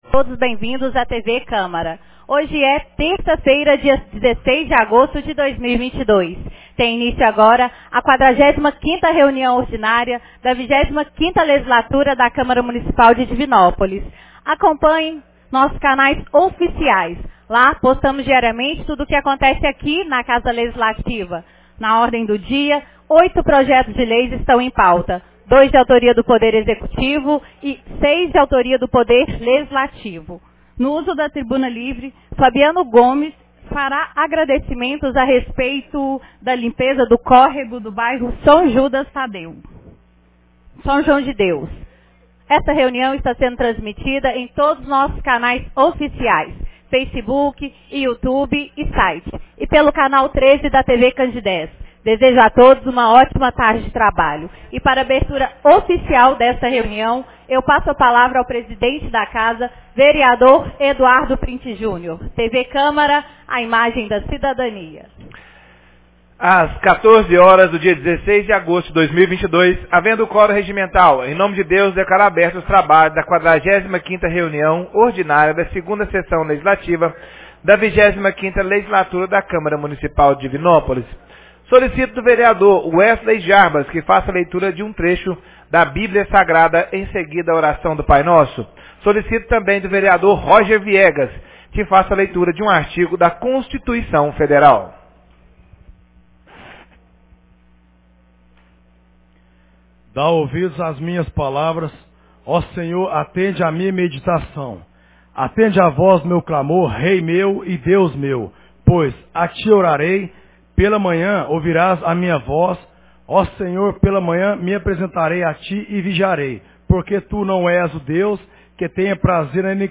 45ª Reunião Ordinária 16 de agosto de 2022